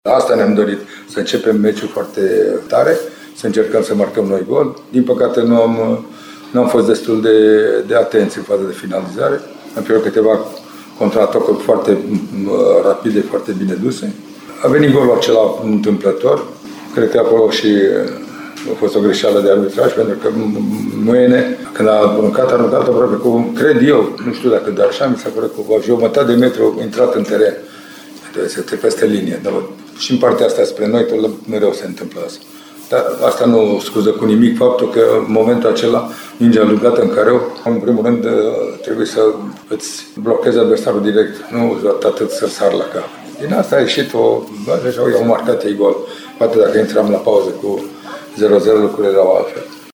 Chiar dacă Austria a avut o sumedenie de ocazii mai ales în prima repriză, una chiar cu câteva secunde înainte de deschiderea scorului, selecționerul Mircea Lucescu spune că reușita lui Gregoritsch a venit întâmplător:
1-Lucescu-primul-gol-intamplator.mp3